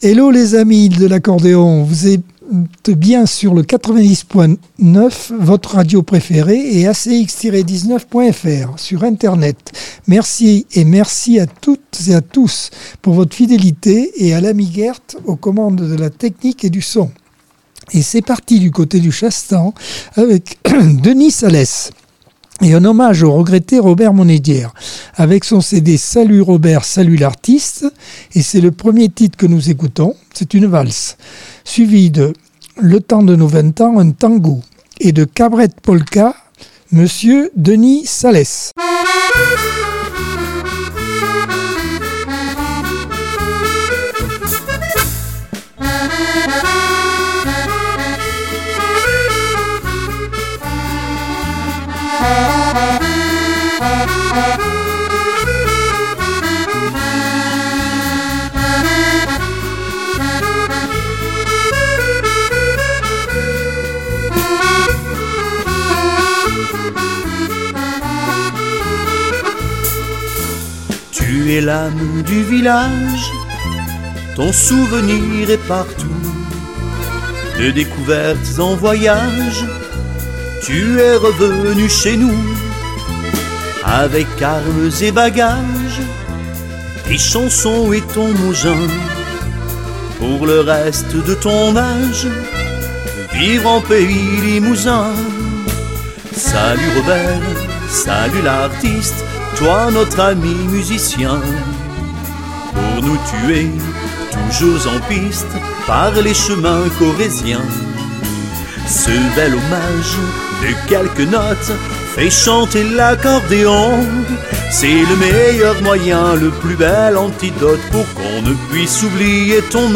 Accordeon 2024 sem 34 bloc 1 - Radio ACX